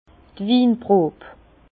Prononciation 67 Herrlisheim